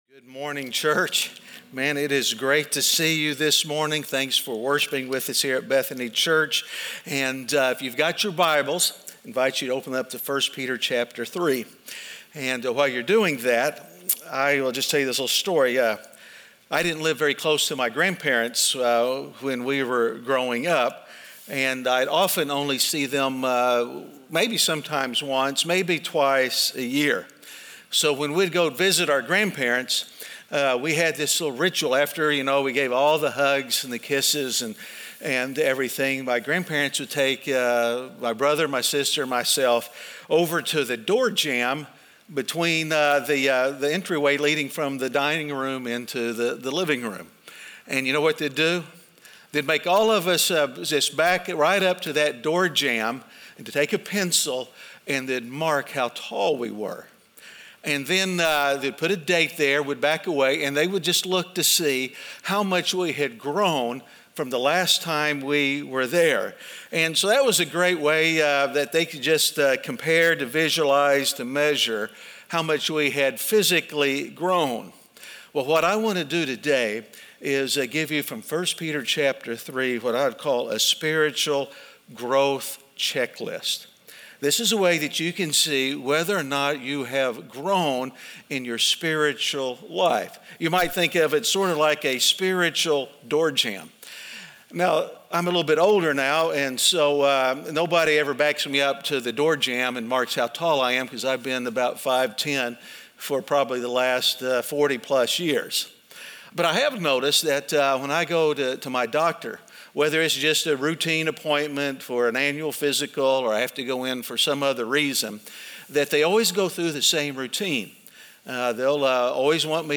Standing Firm In Shaky Times (Week 10) - Sermon.mp3